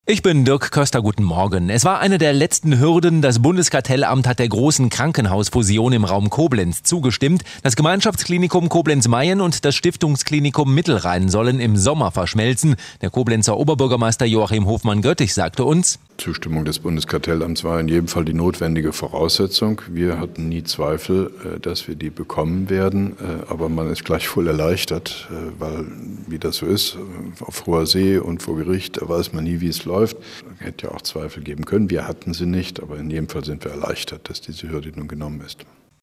Mit Kurzinterview von OB Hofmann-Göttig